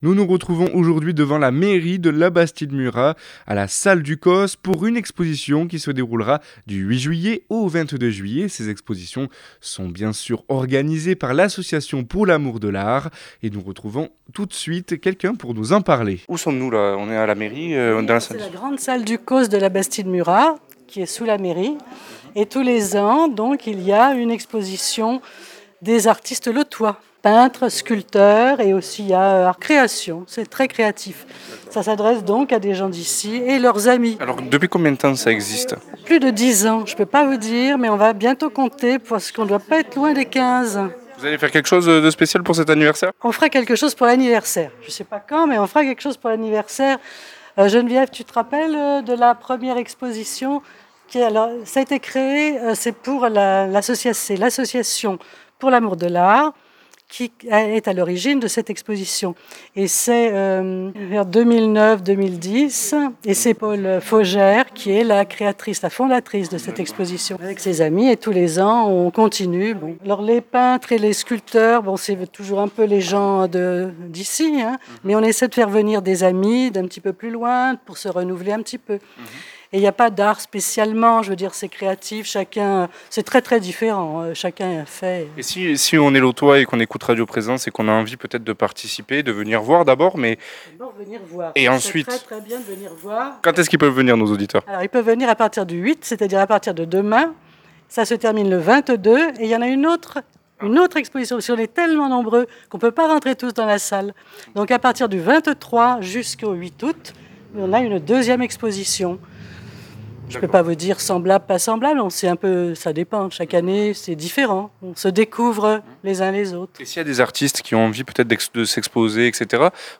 Nous sommes partis à la rencontre des amoureux de l’art, au sein de l’exposition d’art de Labastide Murat organisé par l’association pour l’amour de l’art.